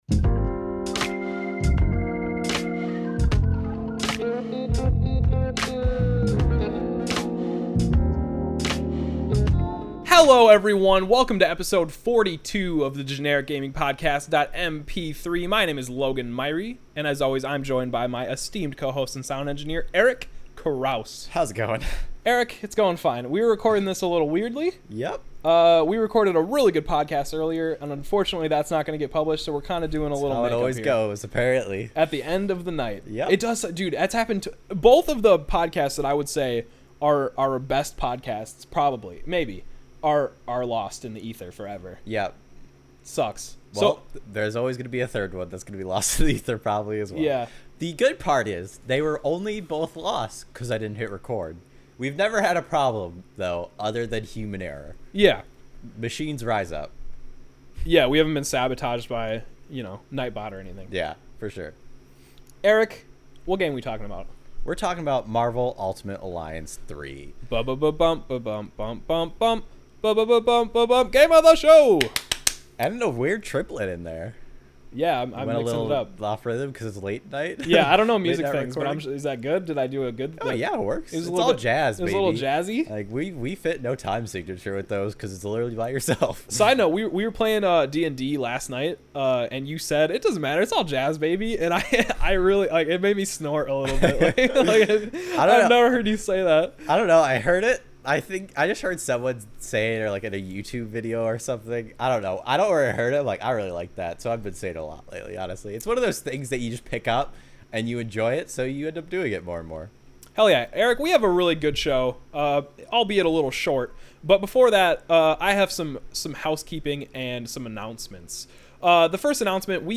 So, what you’re hearing is a re-record of our original podcast for today.